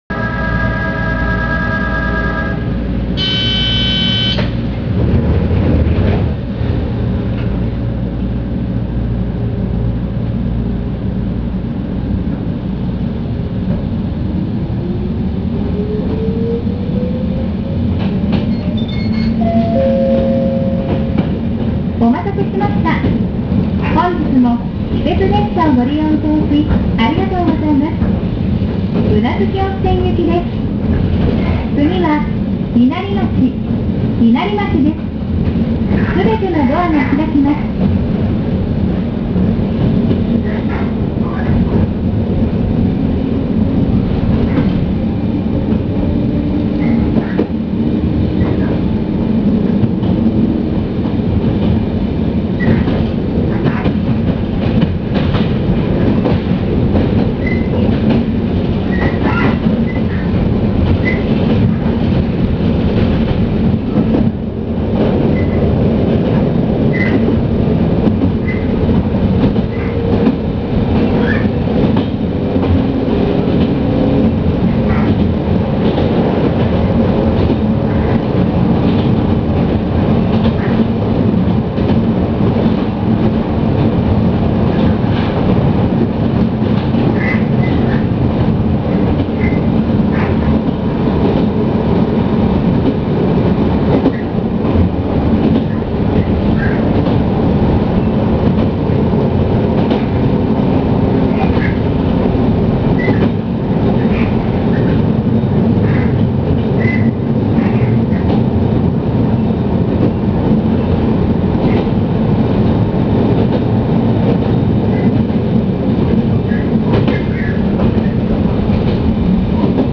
〜車両の音〜
・10030形走行音
【本線】電鉄富山→稲荷町（2分45秒：898KB）
台車や主電動機は交換されているので本来の京阪電車の音ではありません。ワンマン化されているので自動放送つき。ドアブザーがなんとも賑やか。